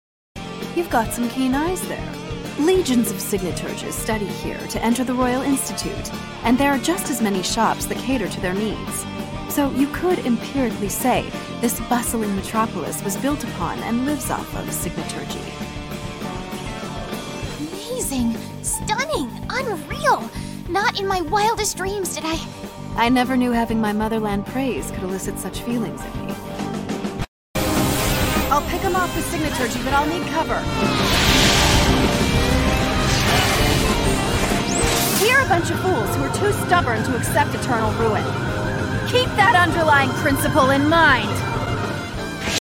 She has voiced everything from promos to cartoons to video games and narration.
Video games - EN